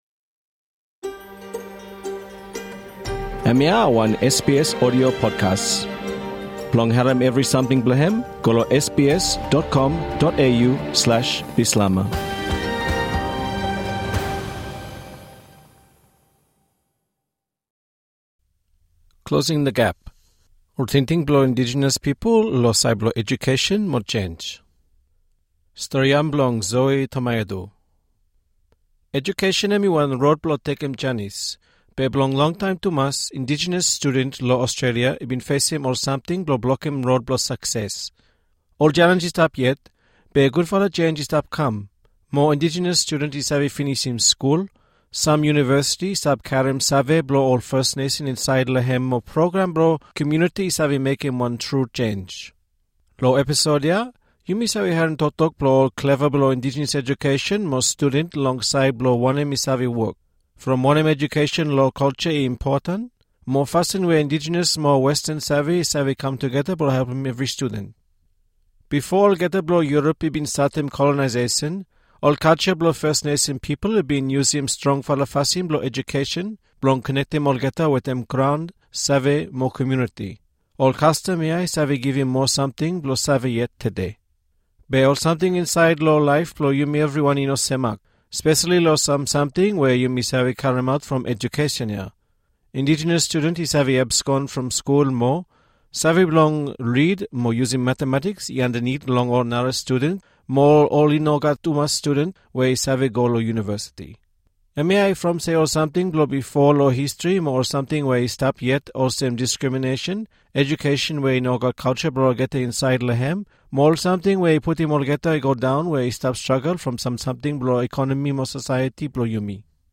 Moa Indijenis studen i save finisim skul, sam yuniversiti i stap karem save blo ol Fes Nesen insaed lem mo prokram blo komuniti i save mekem wan tru jenj. Lo episod ia, yumi save harem toktok blo ol kleva blo Indijenis edukesen mo studen long saed blo wanem i save wok, from wanem edukesen lo kalja i importan, mo fasin we Indijenis mo Westen save i save kam tugeta blo helpem evri studen.